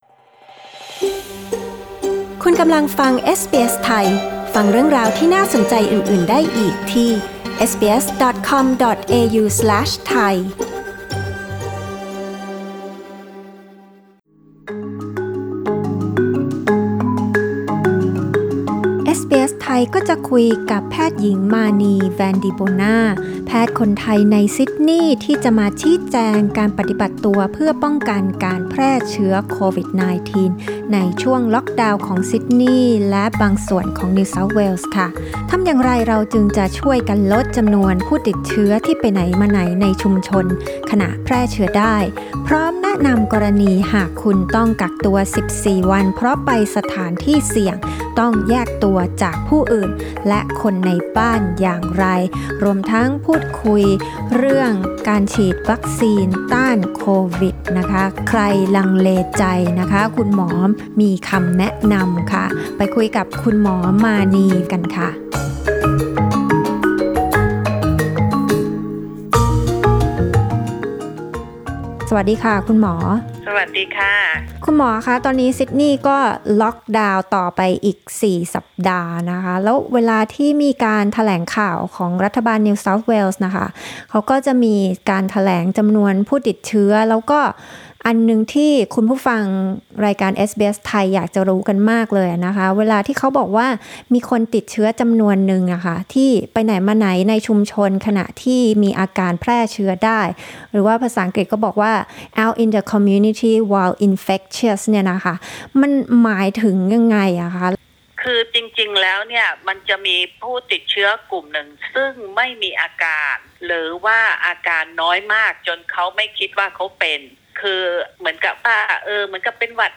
ฟังการพูดคุยกับคุณหมอ